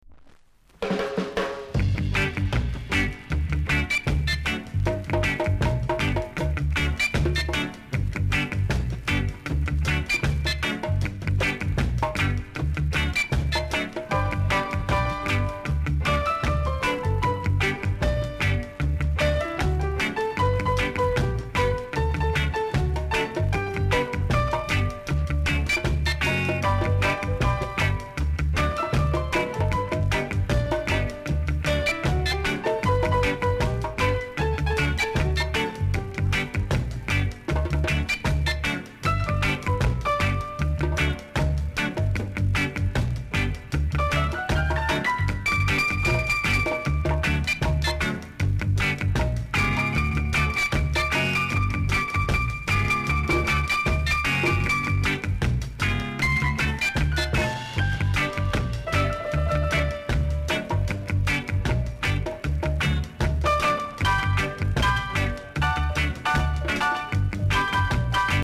※多少ジリジリします。ほか小さなチリノイズが少しあります。
コメント BIG ROCKSTEADY!!